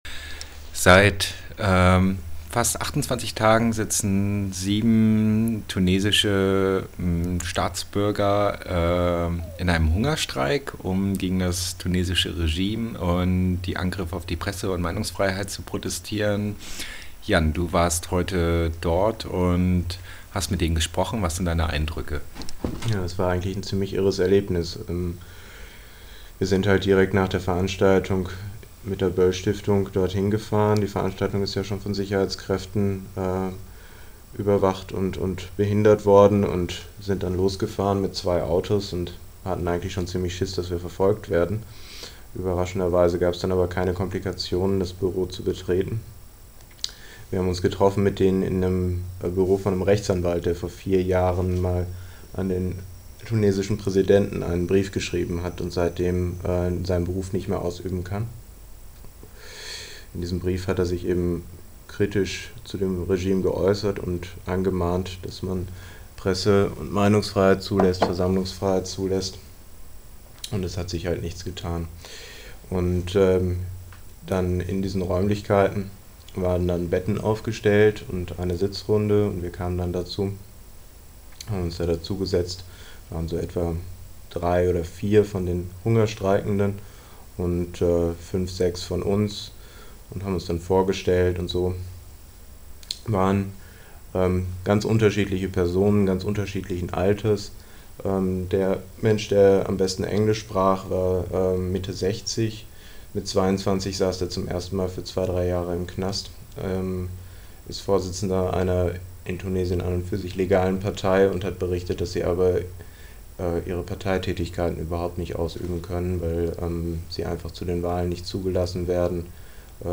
podcasting_bericht_vom_hungerstreik_in_tunesien.mp3